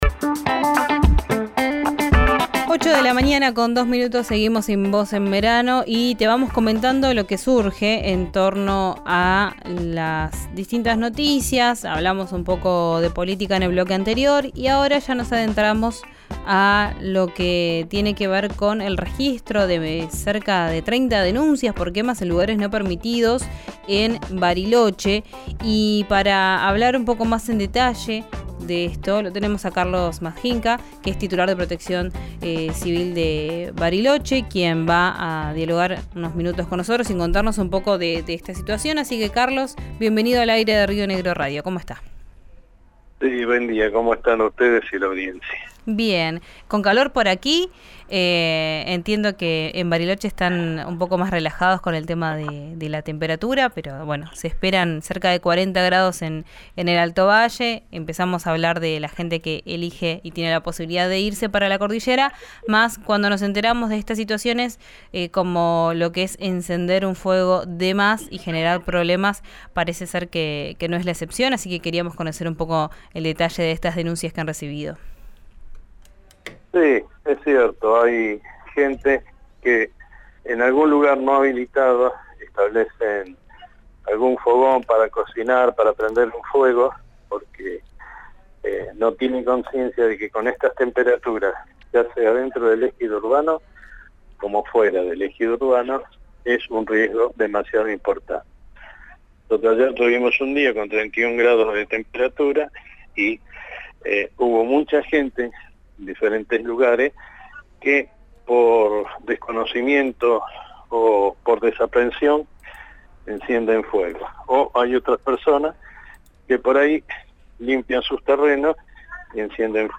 Madjinca, se refirió a la situación en diálogo con Radio Seis, destacando la preocupación por el aumento de denuncias.